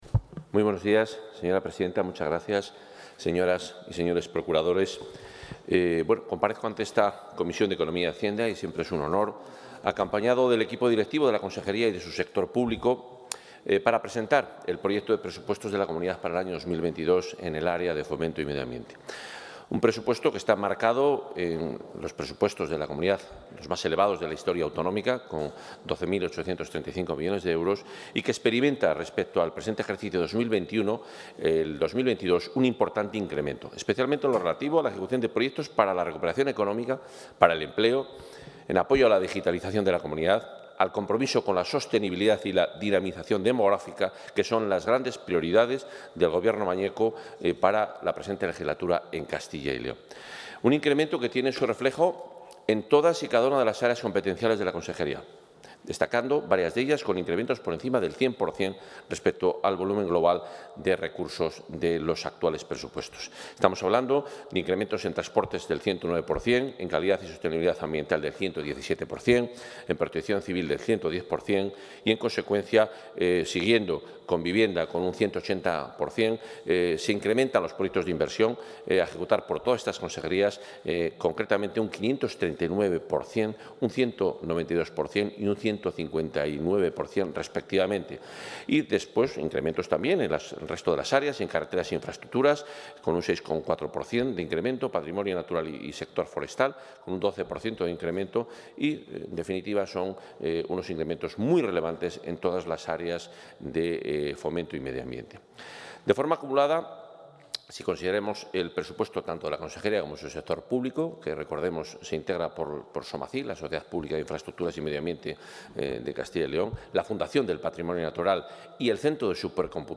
En su comparecencia ante la Comisión de Economía y Hacienda de las Cortes Regionales, Juan Carlos Suárez-Quiñones ha...
Comparecencia del consejero de Fomento y Medio Ambiente.